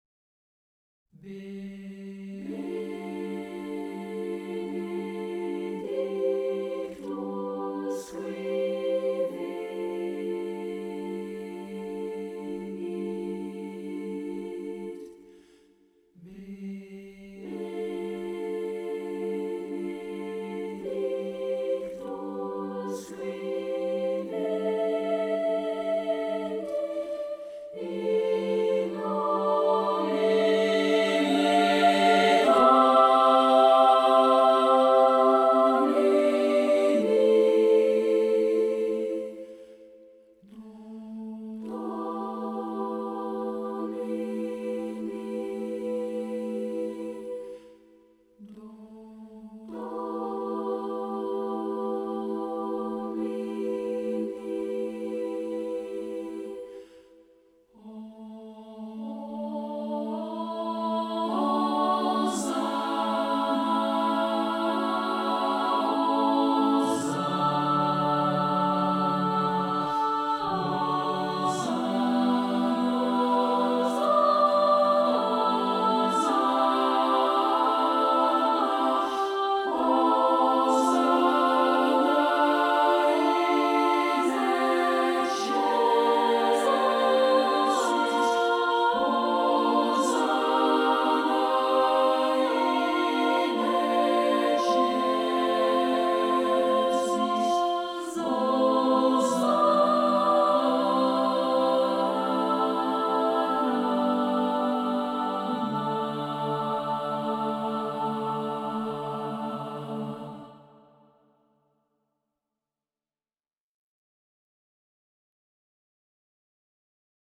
Klassieke muziek
Missa Brevis (voor gemengd koor)